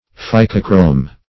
Search Result for " phycochrome" : The Collaborative International Dictionary of English v.0.48: Phycochrome \Phy"co*chrome\, n. [Gr. fy^kos seaweed + chrw^ma color.]